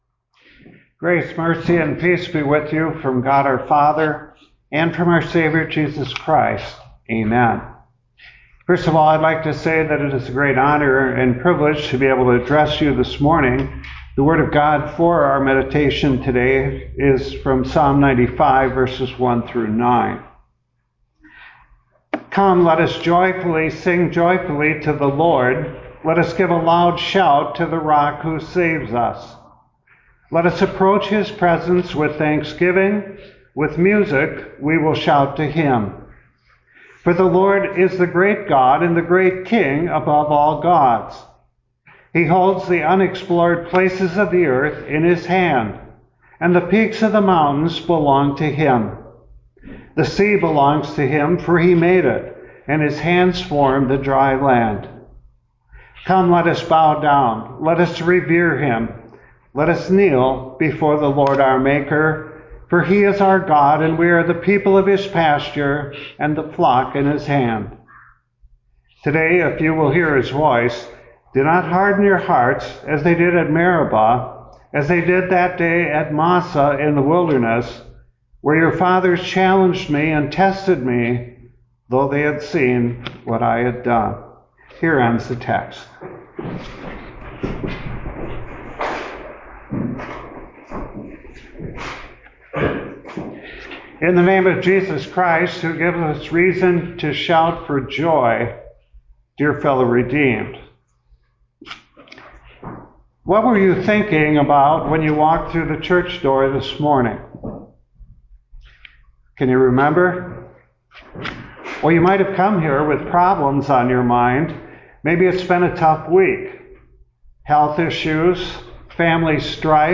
First-Sunday-After-Easter.mp3